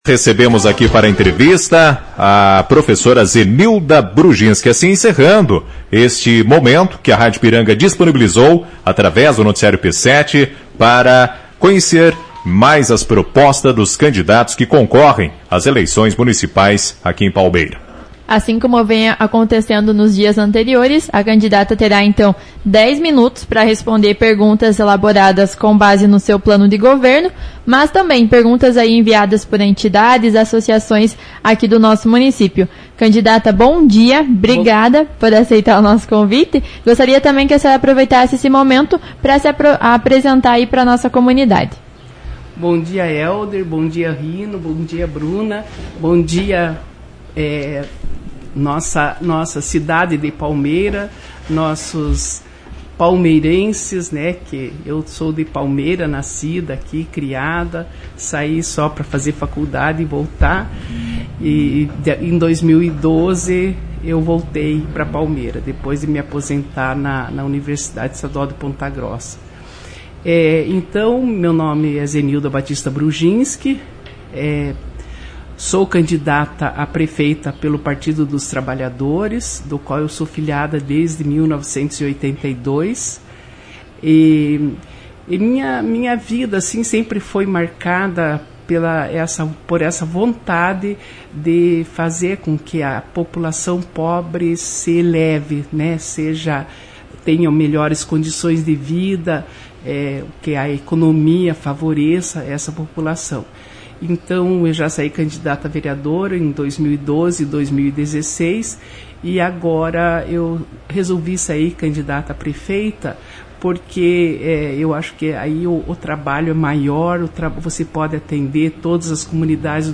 As participações dos(as) Prefeituráveis tiveram o objetivo de explicarem para a população as suas propostas para município. Acompanhe a entrevista na íntegra: